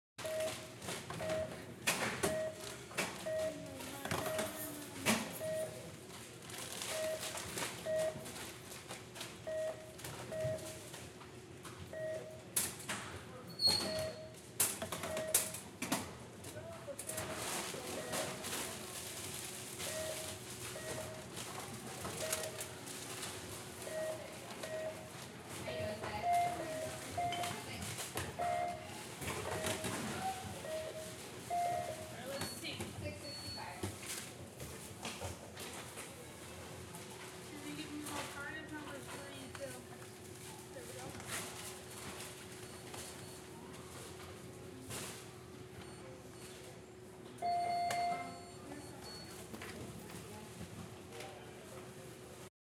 AMBMrkt_Checkout, Supermarket, Light Conversation_UberDuo_MRKT.ogg